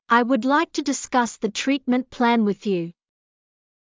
ｱｲ ｳｯﾄﾞ ﾗｲｸ ﾄｩ ﾃﾞｨｽｶｽ ｻﾞ ﾄﾘｰﾄﾒﾝﾄ ﾌﾟﾗﾝ ｳｨｽﾞ ﾕｰ